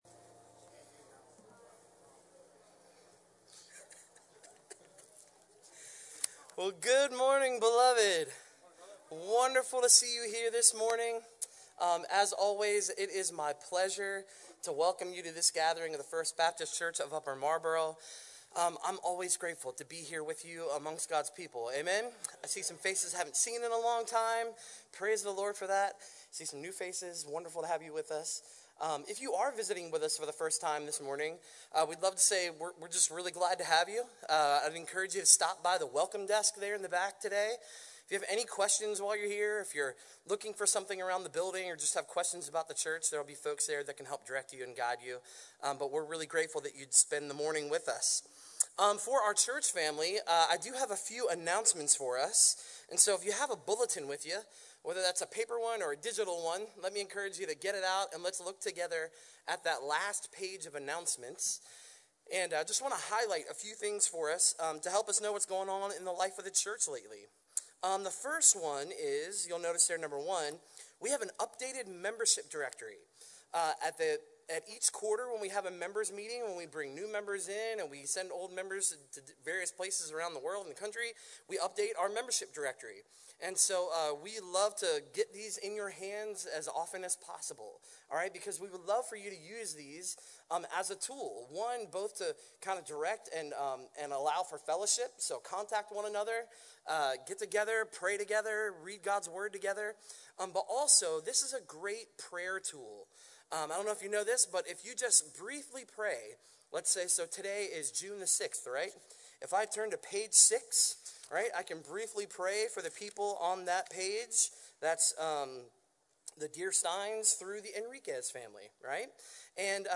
Sermon Outline I. The call to continue with Jesus.